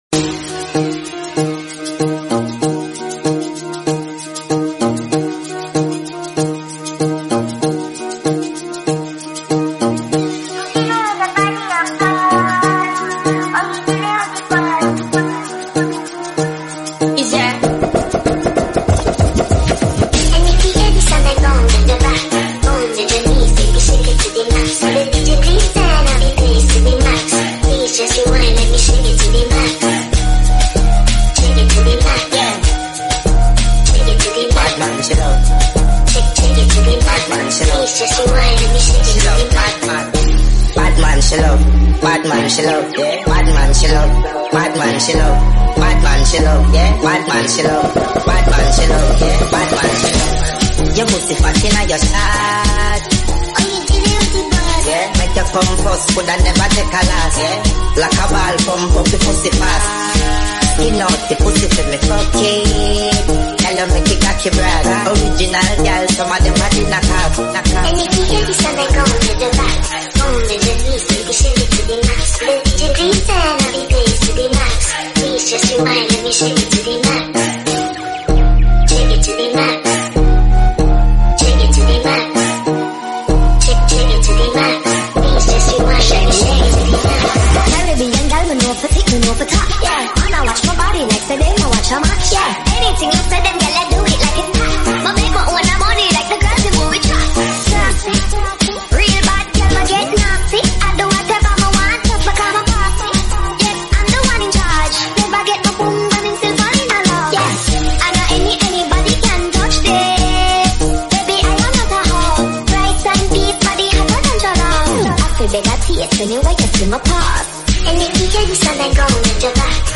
AMAPIANO
FULLBASS SLOWLY